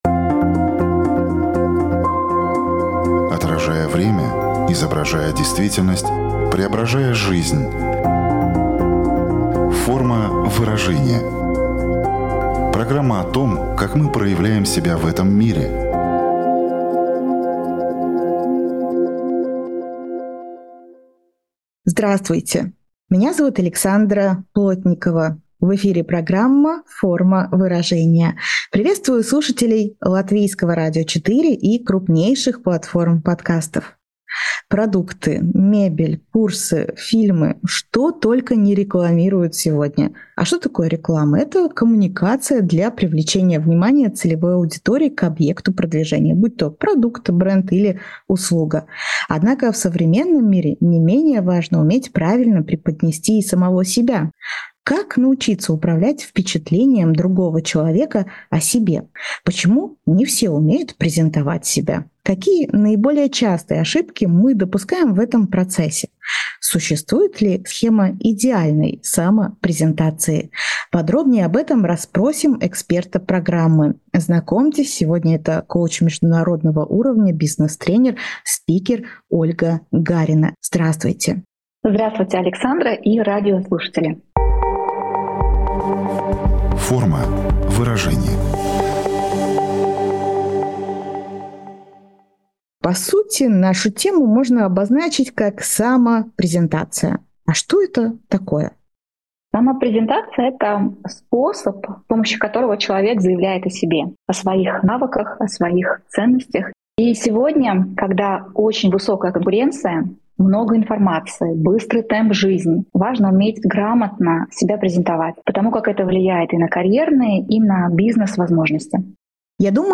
Программа "Форма выражения" - разговор с человеком о человеке о том, как мы выражаем себя в этом мире.